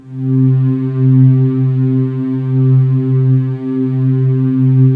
Index of /90_sSampleCDs/USB Soundscan vol.28 - Choir Acoustic & Synth [AKAI] 1CD/Partition D/11-VOICING